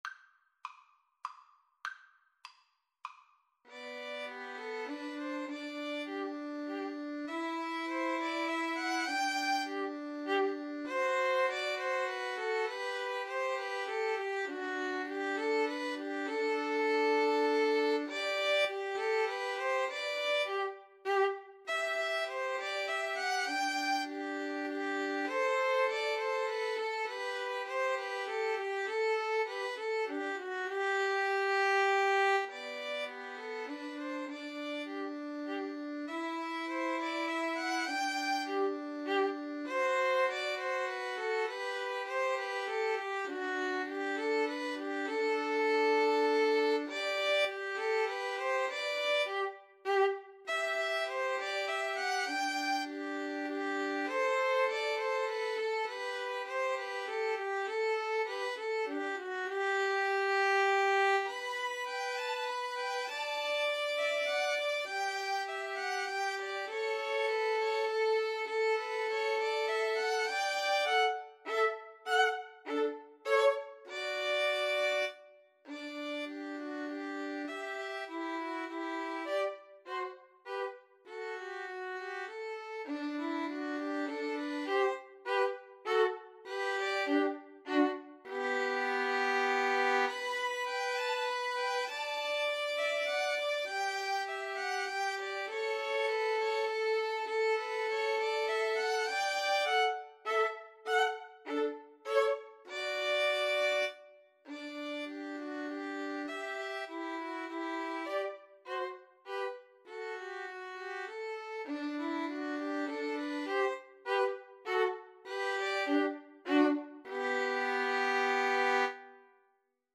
Classical (View more Classical 2-violins-viola Music)